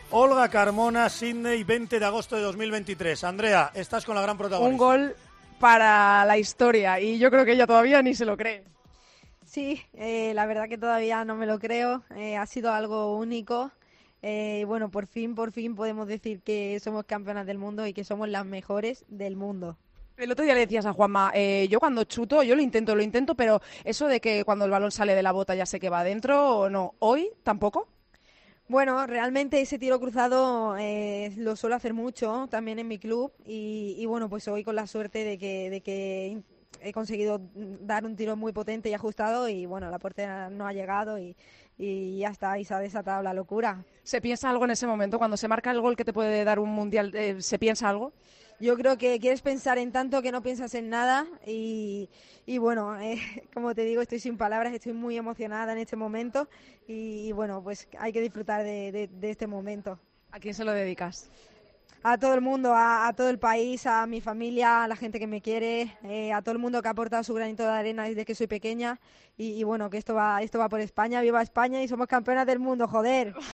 La autora del gol que dio la Copa del Mundo a España habló en Tiempo de Juego de la importancia de ese tanto y la emoción que supone ser las mejores del mundo.